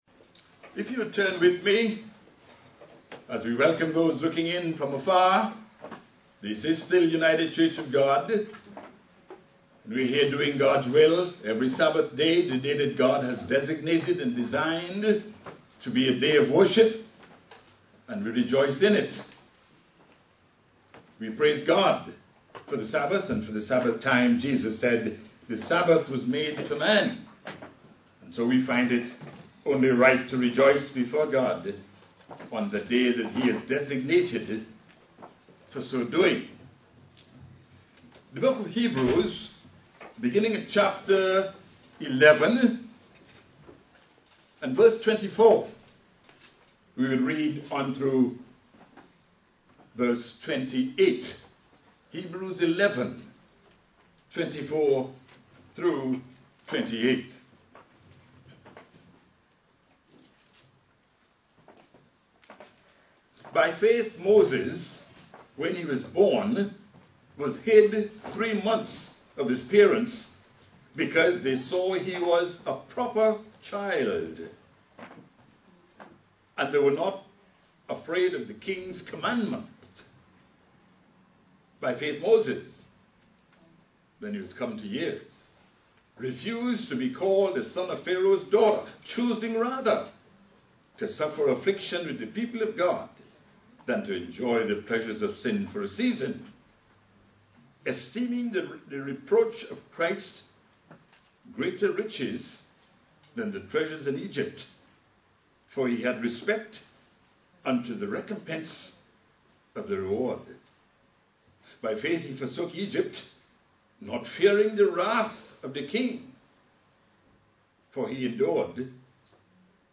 Given in New York City, NY
UCG Sermon Studying the bible?